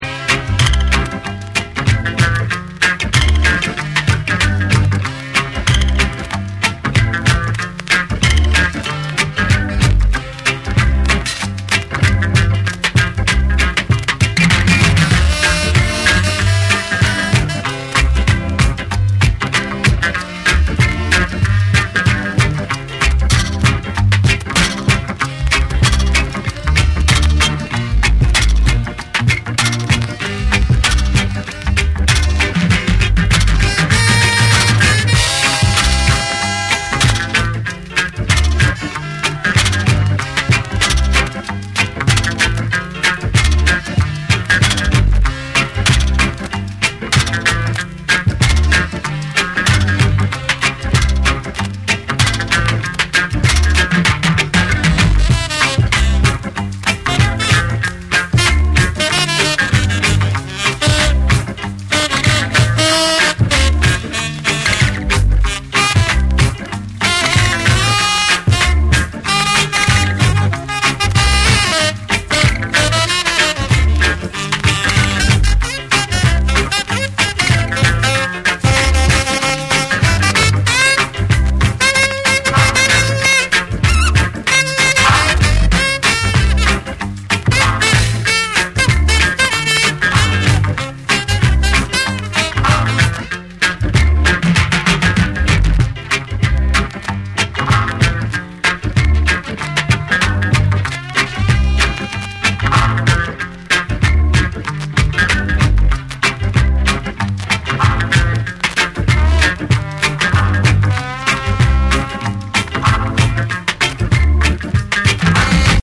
心地よいグルーヴに爽快なサックスが舞うインスト・レゲエを披露！
REGGAE & DUB